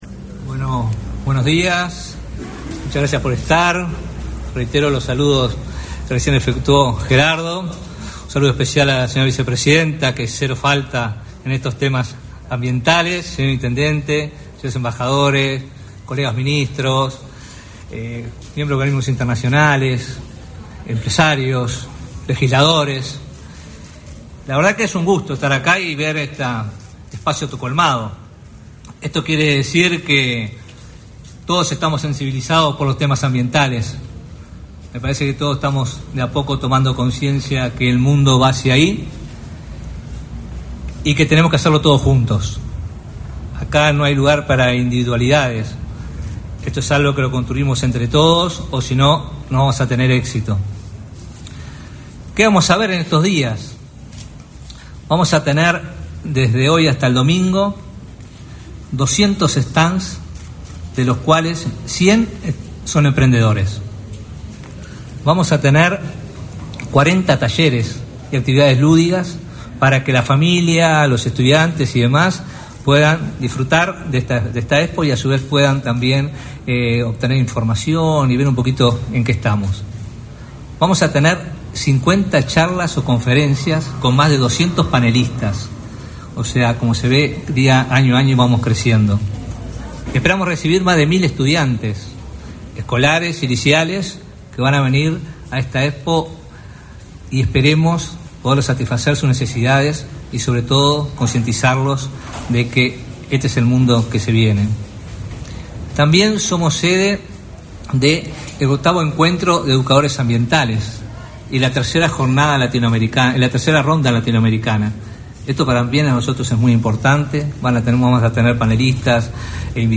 Palabras del ministro de Ambiente, Robert Bouvier
Palabras del ministro de Ambiente, Robert Bouvier 06/06/2024 Compartir Facebook X Copiar enlace WhatsApp LinkedIn Este 6 de junio, comenzó la Expo Uruguay Sostenible, con la participación de la vicepresidenta de la República, Beatriz Argimón. En la apertura, se expresó el ministro de Ambiente, Robert Bouvier.